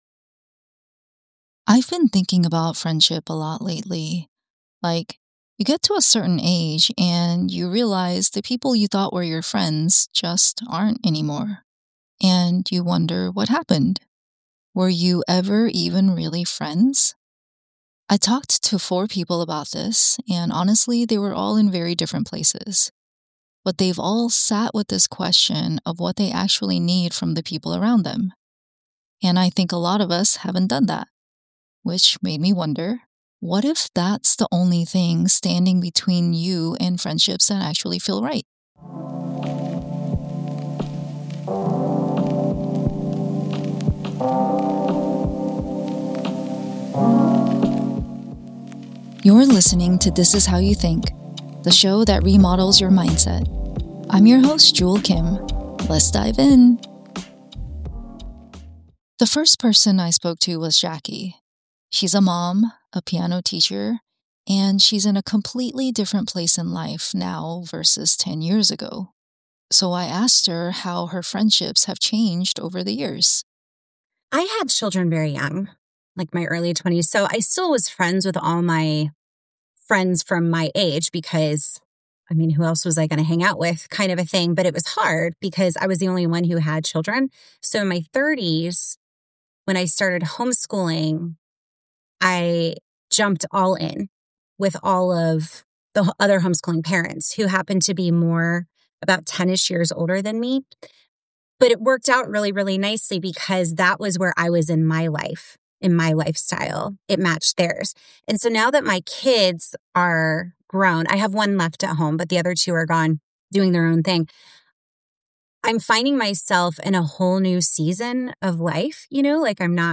I sat down with four people to explore their experiences with friends to answer exactly that.